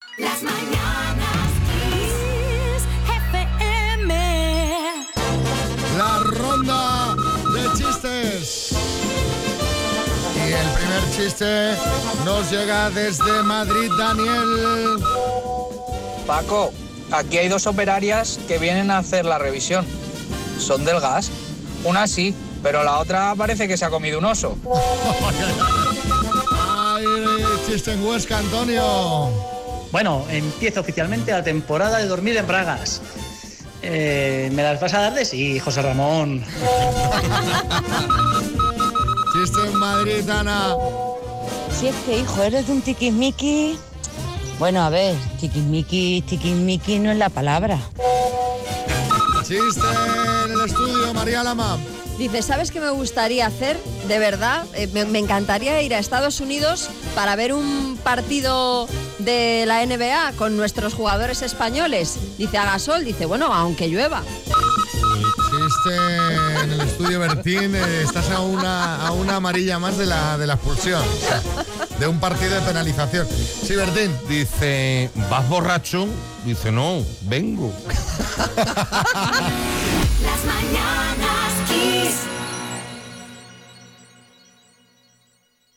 La ronda de chistes hace parada hoy en Madrid y Huesca
Nos hemos reído, y mucho, con unas operarias del gas.